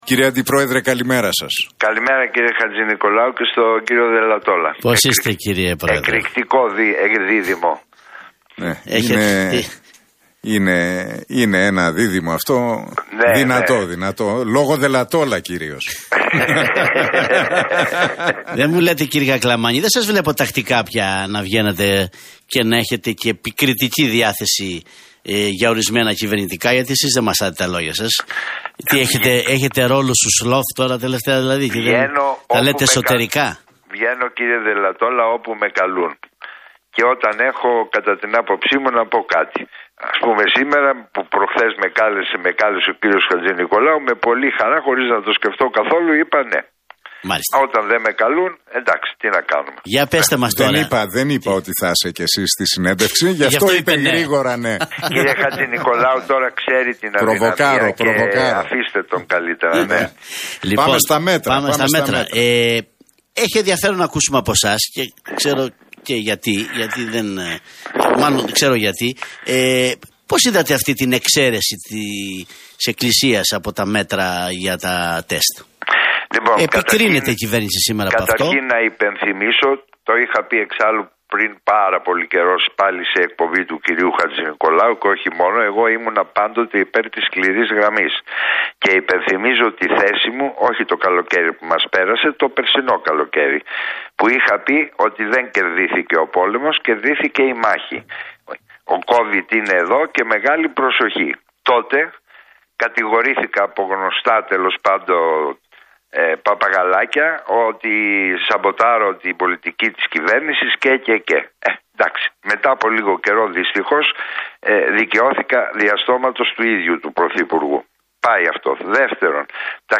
Κακλαμάνης στον Realfm 97,8: Αν δεν κάνουμε την τρίτη δόση "κινδυνεύουμε" να βρεθούμε ενώπιον πανδημίας των εμβολιασθέντων